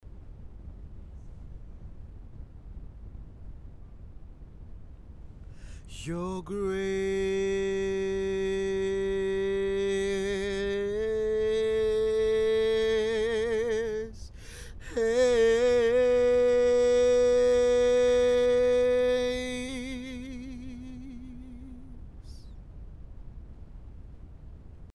Шум Audio Technica 4060 - проблема или издержки лампы?
И вот я покупаю АТ 4060, который имеет аналогичный шум, который ну очень сильно мешает на записях вокала тихой музыки, особенно с минимумом инструментом или очень тихих партий.
К сообщению прилагаю примеры шума.
Всем спасибо за ответы Вложения noise4060_01.mp3 noise4060_01.mp3 1,8 MB · Просмотры: 429 noise4060_02.mp3 noise4060_02.mp3 583,8 KB · Просмотры: 353